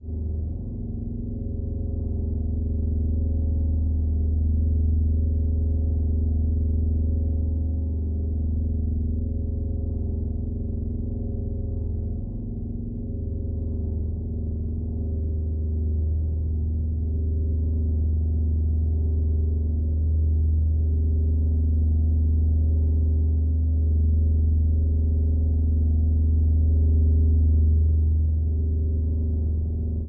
The following audio examples consist of processed, transposed and superimposed tubax sounds amplifying tonal interferences.
Audio Example: Drone in Dis
02_Drone_Dis.mp3